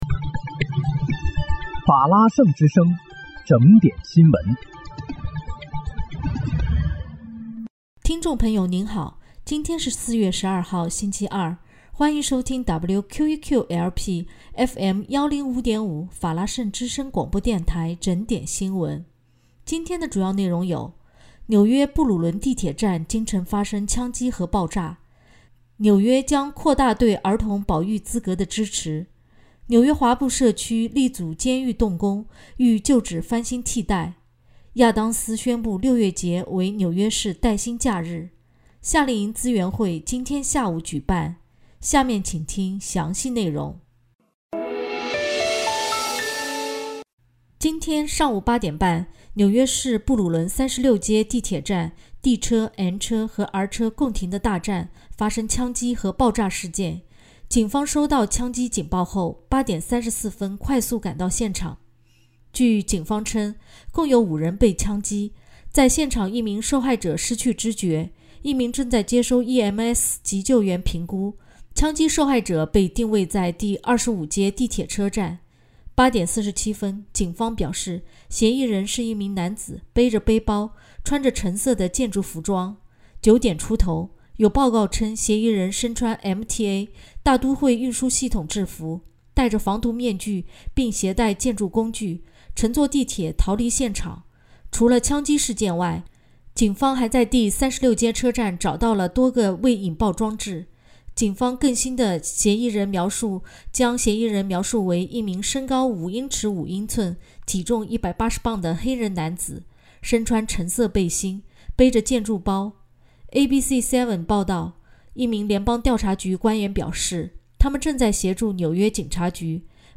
4月12日（星期二）纽约整点新闻